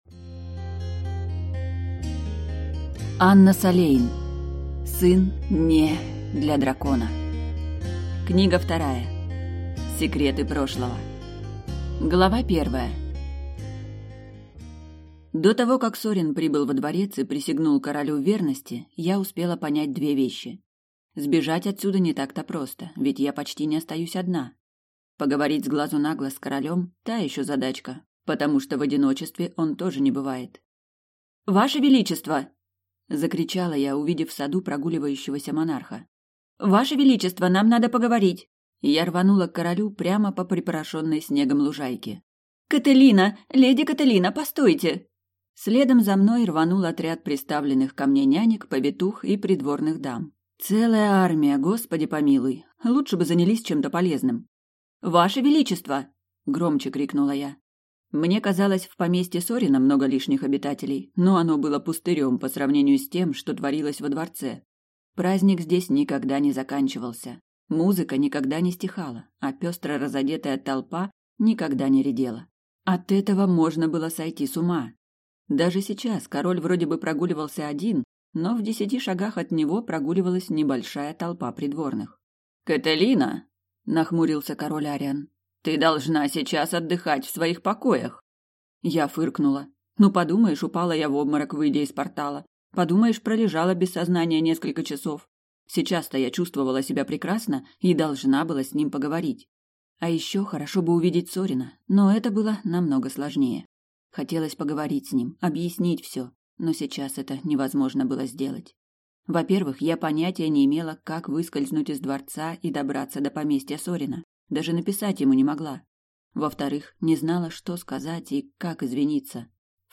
Аудиокнига Сын (не) для дракона. Книга 2. Секреты прошлого | Библиотека аудиокниг